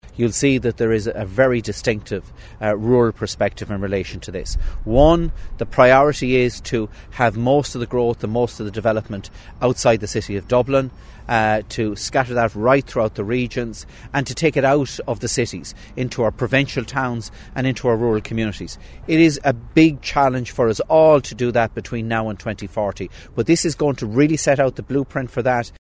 Communications Minister Denis Naughten says despite criticism, he's happy the plan will be good for rural Ireland: